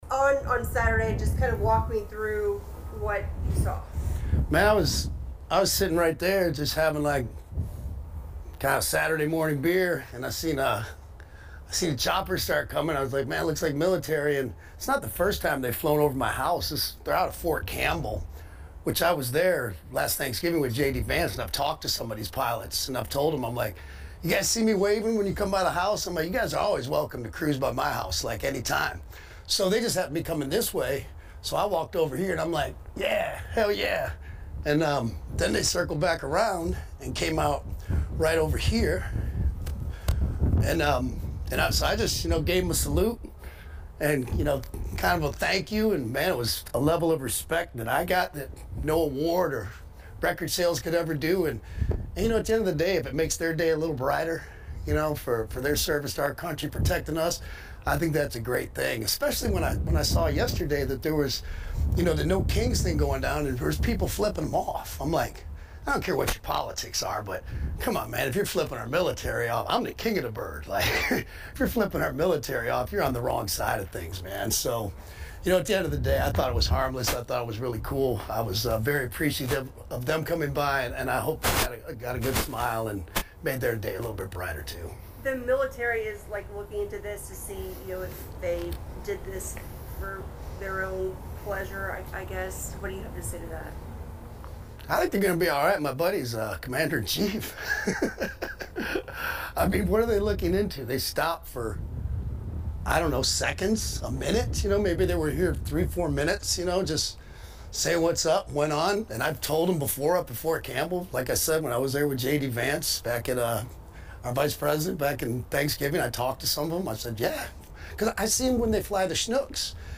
kid-rock-raw-interview-on-helicopter-stop.mp3